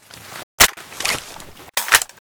ak12_m1_reload.ogg